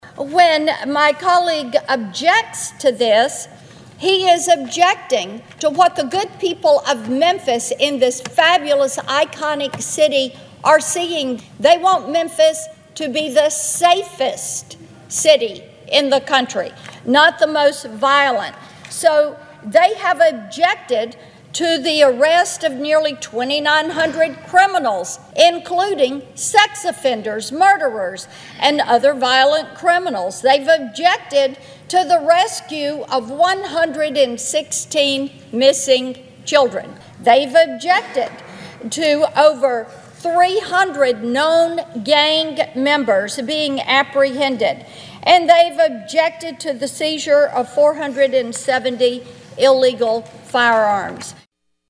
Senator Blackburn had urged colleagues on the floor to support a resolution recognizing the success of the Task Force.
Blackburn informed her Democratic colleagues of the success seen from the Task Force in Memphis.(AUDIO)